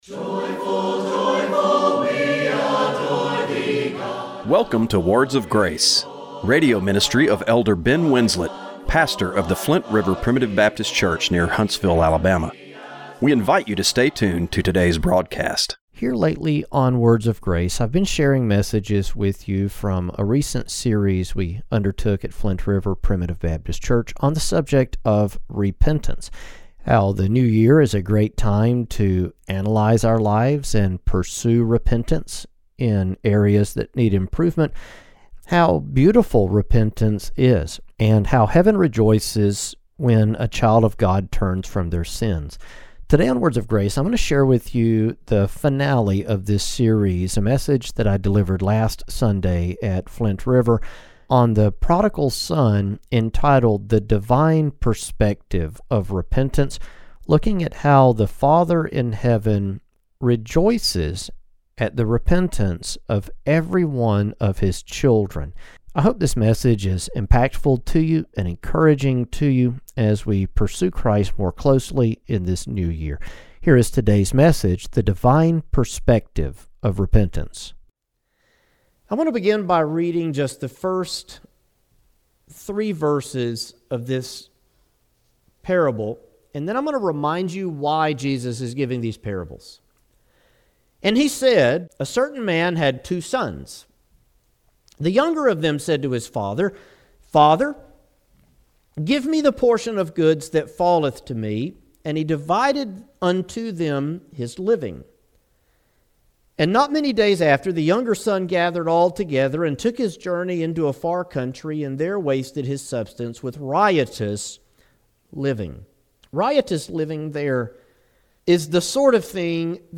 Radio broadcast for January 25, 2025.